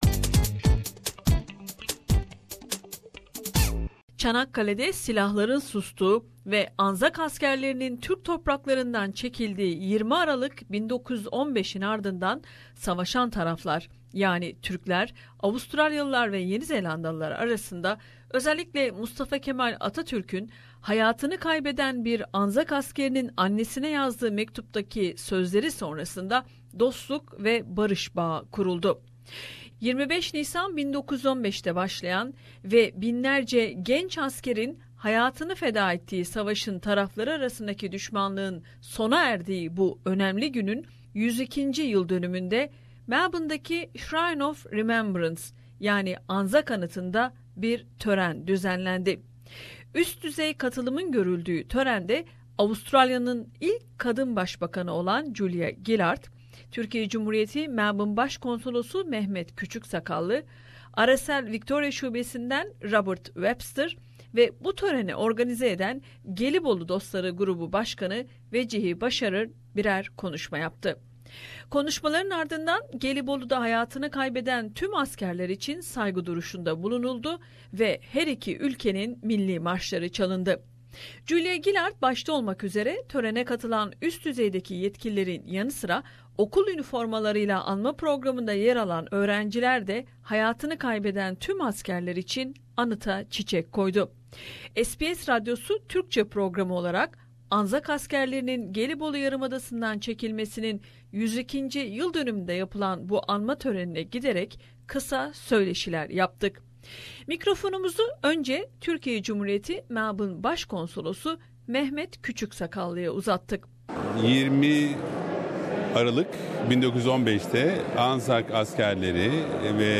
Gelibolu’da silahların sustuğu ve Anzak askerlerinin çekildiği 20 Aralık 1915'in, 102. yıldönümünde Melborune'deki Anzak anıtında yapılan törene, Avustralya’nın ilk kadın basbakanı Julialı Gillard da katılarak bir konuşma yaptı.
söyleşiler yaptık.